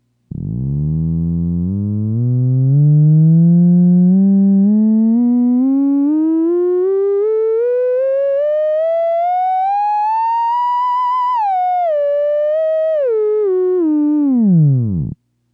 For the best theremin sound it must begin with a sine wave at Out-2B
This sine wave was taken from Out-2B of the pitch board which is before the audio transformer Enhancement stage.
whistle sound .mp3 190k
sinewave.mp3